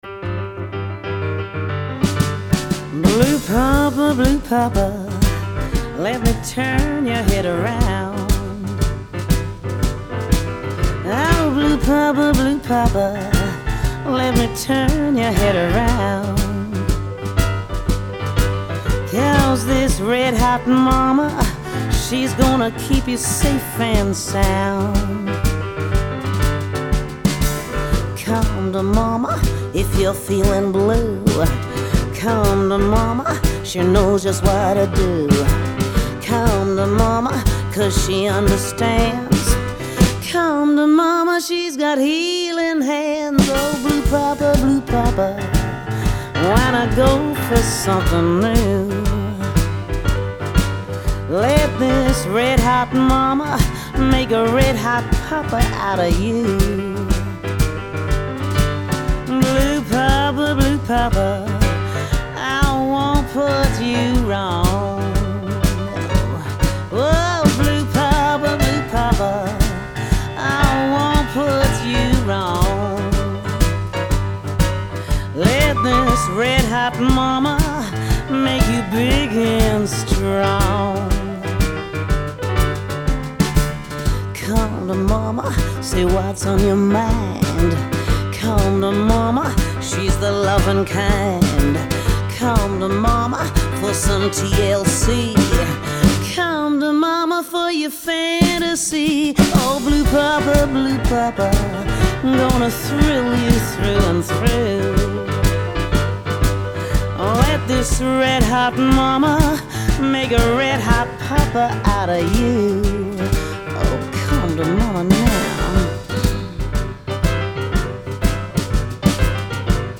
Блюзы и блюзики